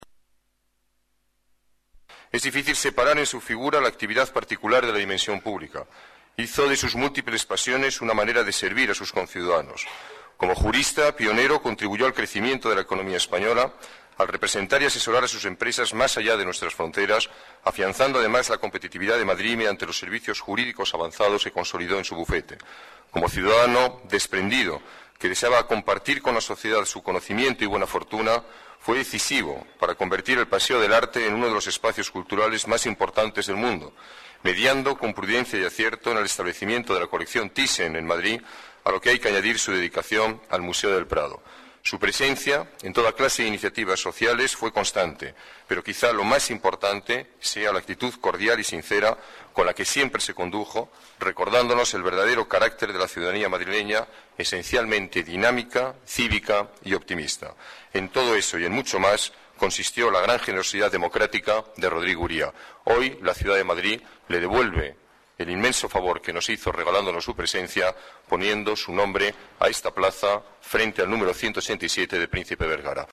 Nueva ventana:Declaraciones del alcalde, Alberto Ruiz-Gallardón